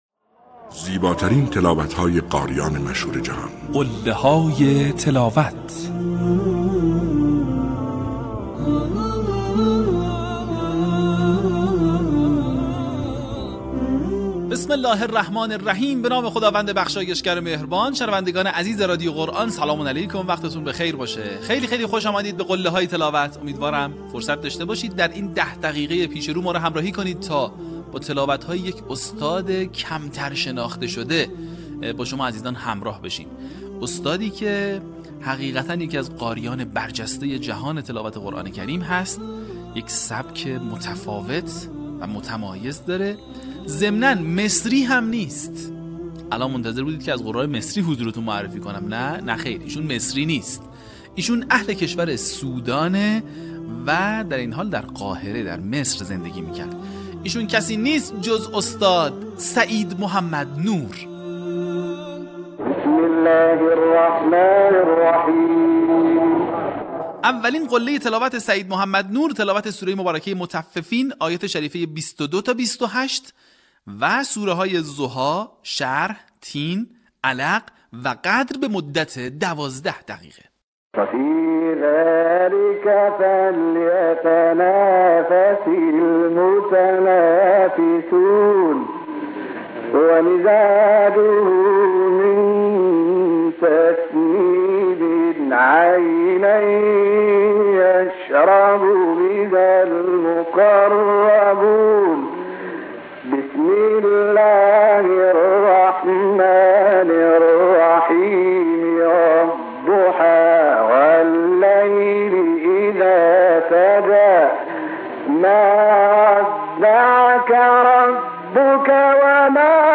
به همین منظور برترین و برجسته‌ترین مقاطع از تلاوت‌های شاهکار قاریان بنام جهان اسلام که مناسب برای تقلید قاریان است با عنوان «قله‌های تلاوت» ارائه و بازنشر می‌شود. در قسمت چهل‌ویکم فراز‌های شنیدنی از تلاوت‌های به‌یاد ماندنی استاد سعید محمد نور را می‌شنوید.